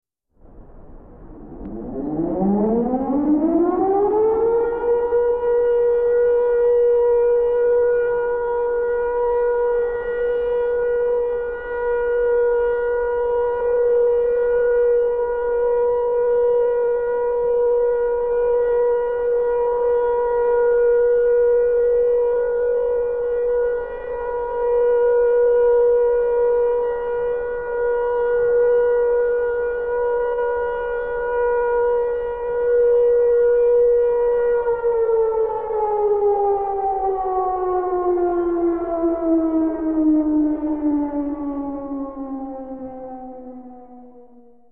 siren.mp3